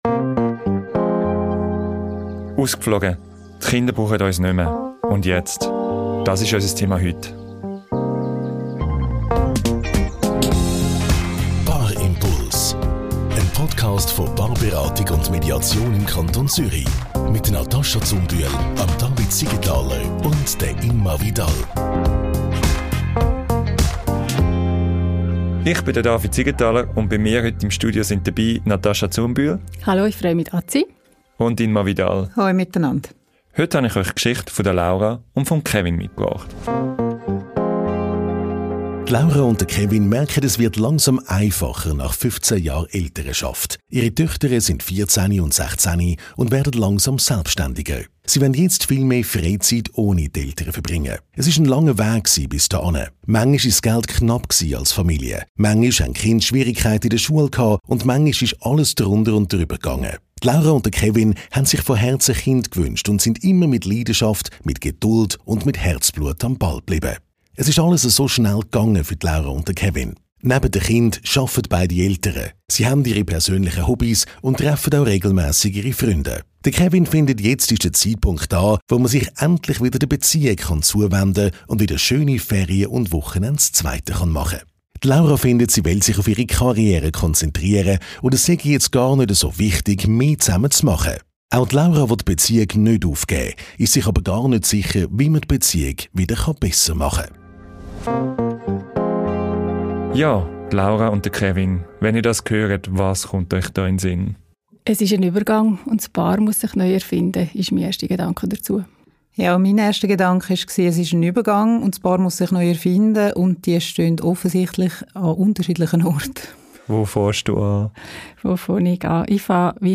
In dieser Folge reflektieren die Paarberater*innen wie der Übergang gelingen und wie man persönliche Wünsche und gemeinsame Ziele miteinander vereinen kann.